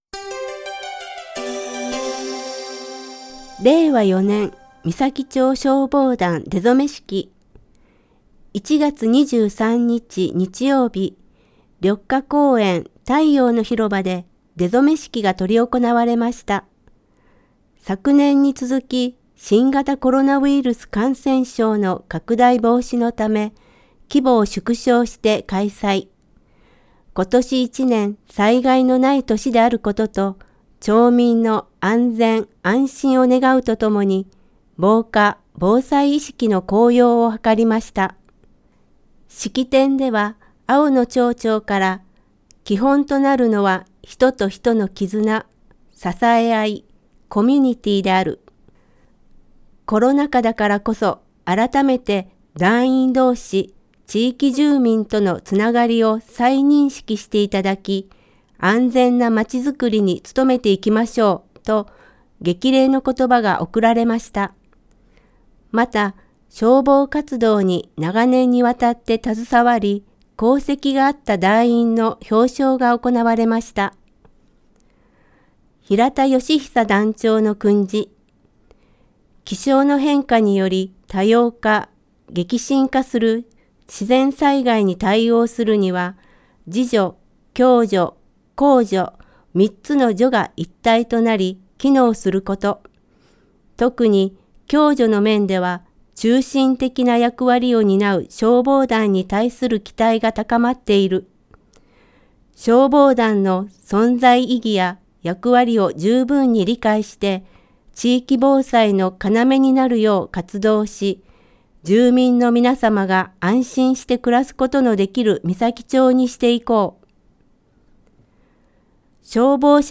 声の広報
広報誌の一部を読み上げています。